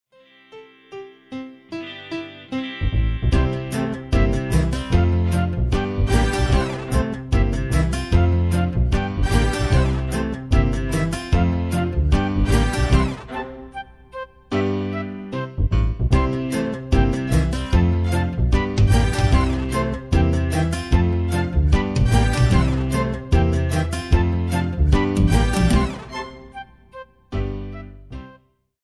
I